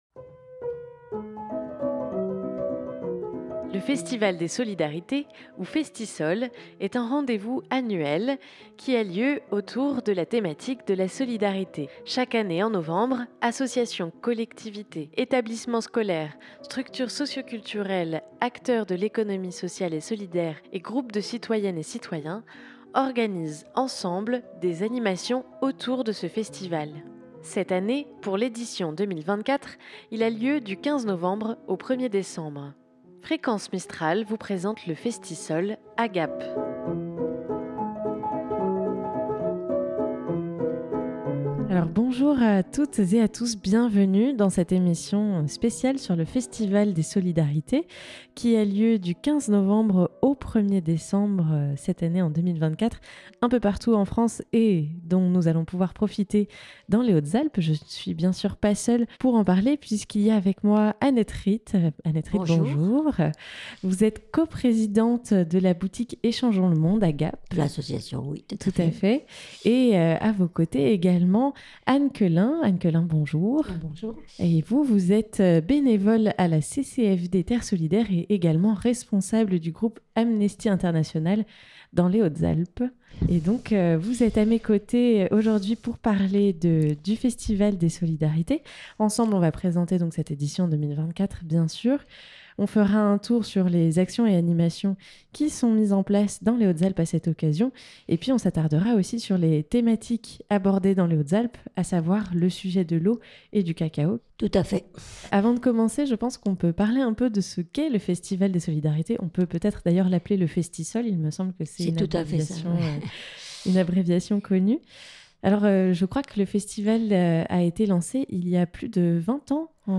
Cette année, dans les Hautes Alpes, le festival s'articulera autour des thématiques plus spécifiques de l'eau et du cacao. Pour en parler, nous avons reçu dans nos studios